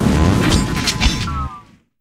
Cri de Carmadura dans Pokémon HOME.